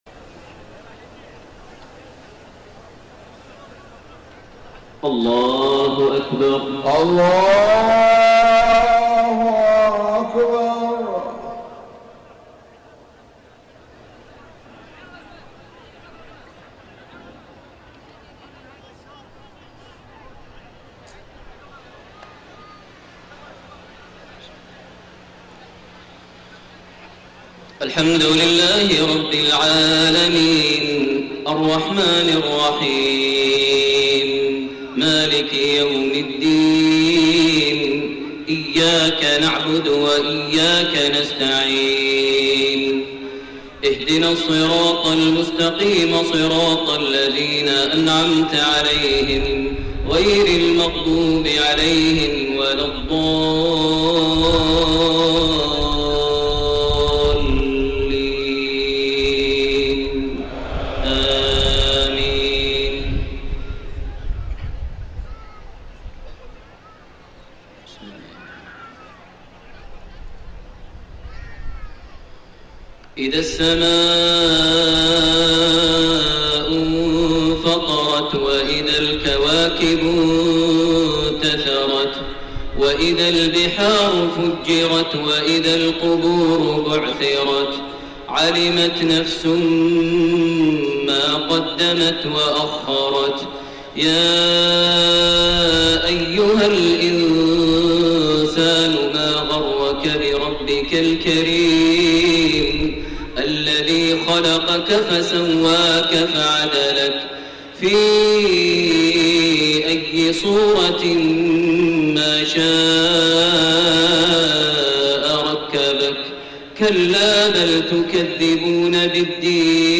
صلاة العشاء2-5-1431 سورتي الانفطار و القارعة > 1431 هـ > الفروض - تلاوات ماهر المعيقلي